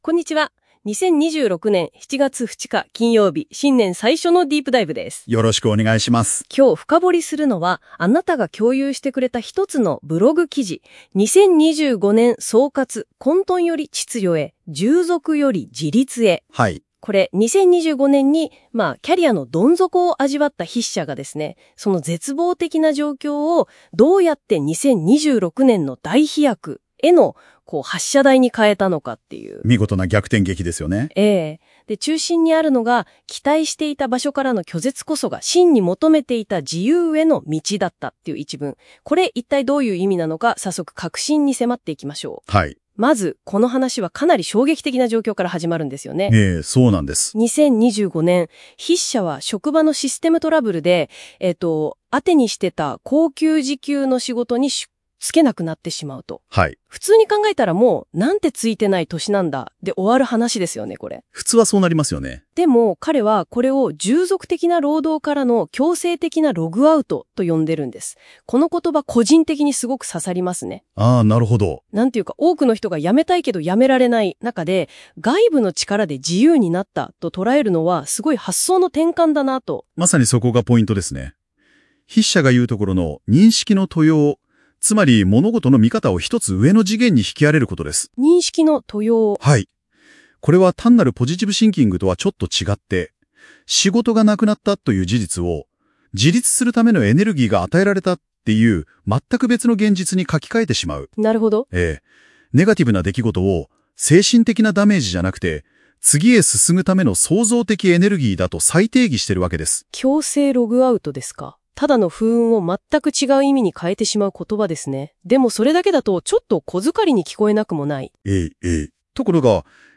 音声解説を追加しました。